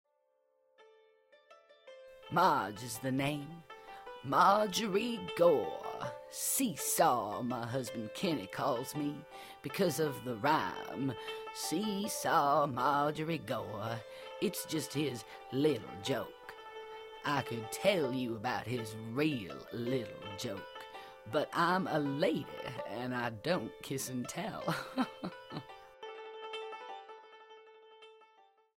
Calm and warm for Documentaries, Sultry and fun for commercials, professional and informative for business, energetic for games.
englisch (us)
Sprechprobe: eLearning (Muttersprache):